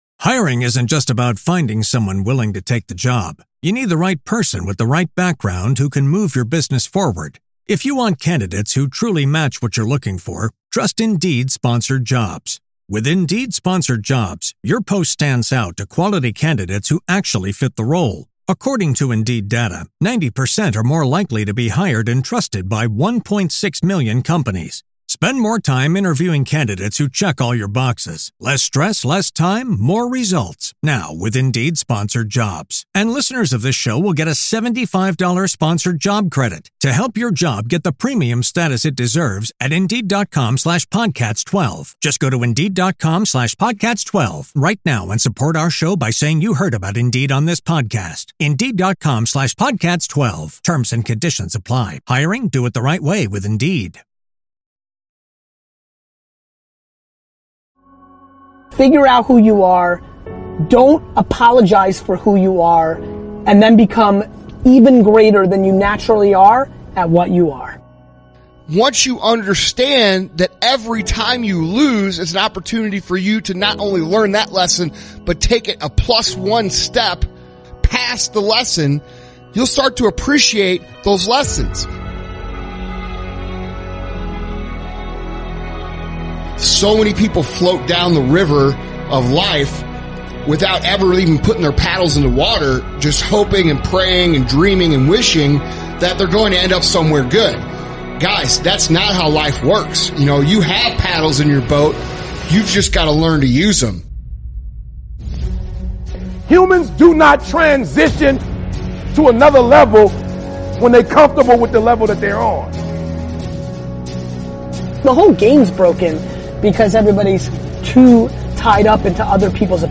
Speakers: Gary Vaynerchuk